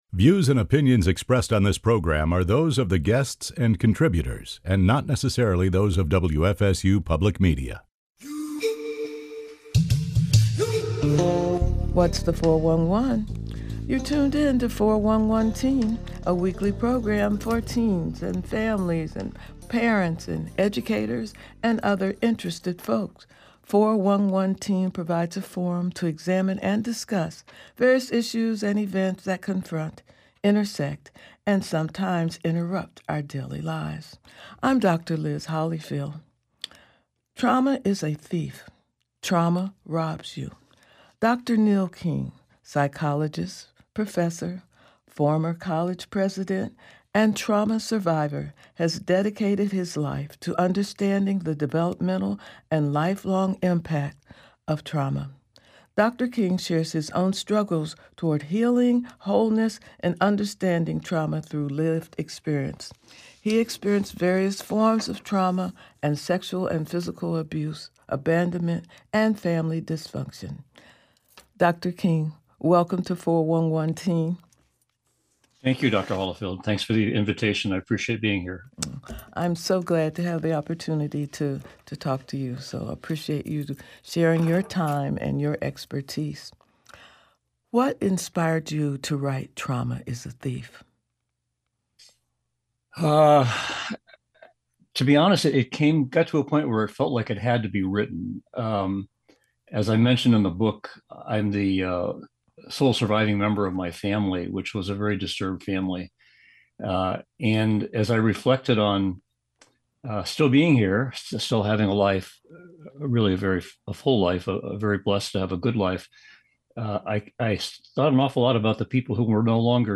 Guests provide insights into topics that concern adolescents.